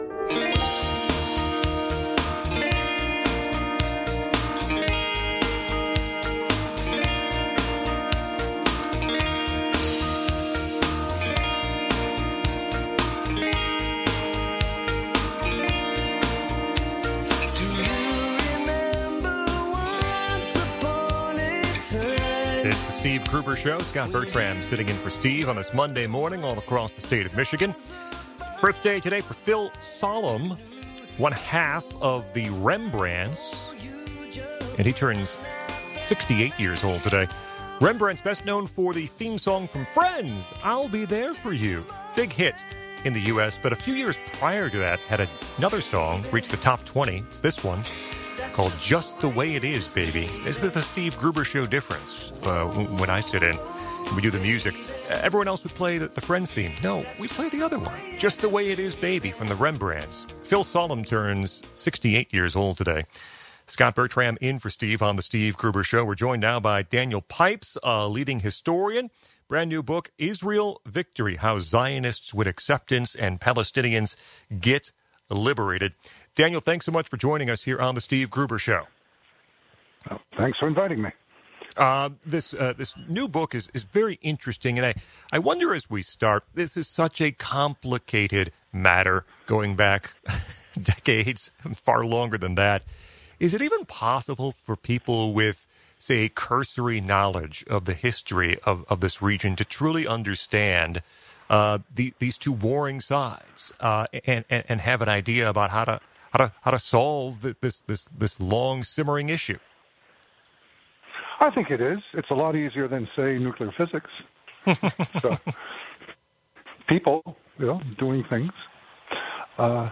Interviews with Daniel Pipes